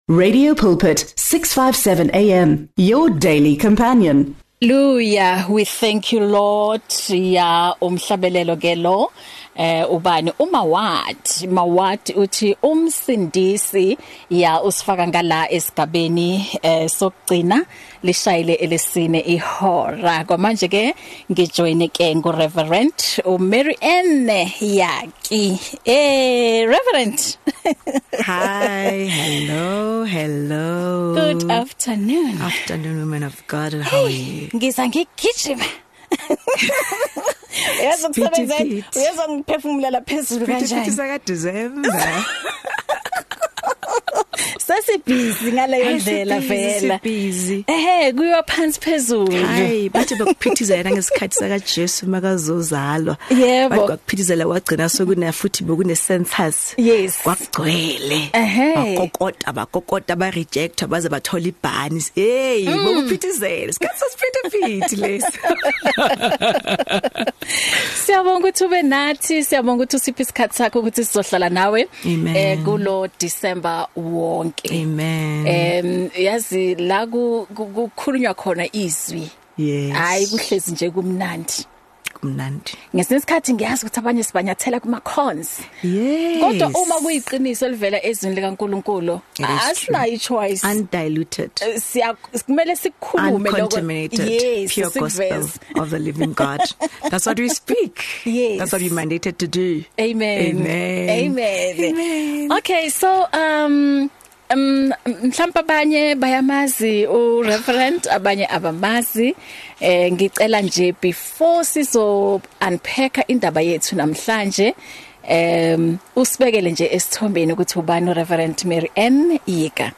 Jula Ezwini (Focus on the Word) is a magazine programme on Radio Pulpit. It comprises of interviews, discussions and music designed to encourage and motivate listeners.